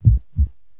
heart_beats